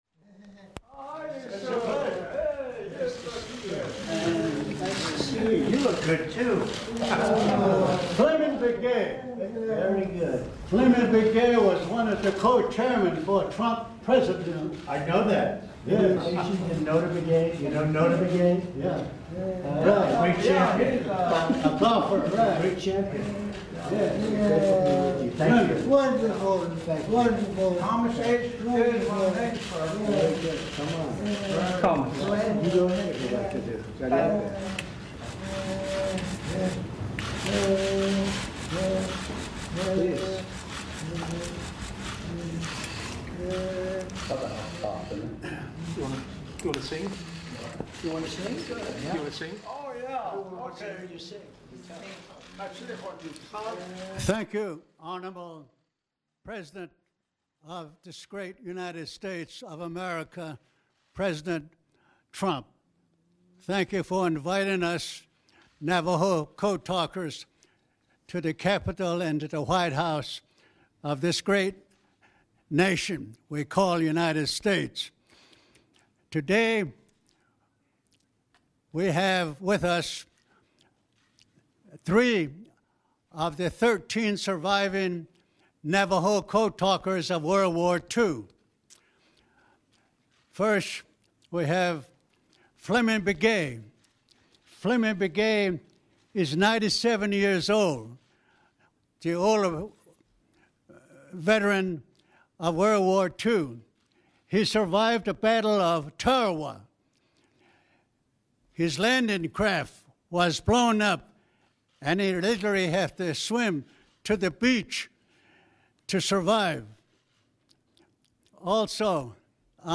White House Oval Office event honoring the World War II Navajo Code Talkers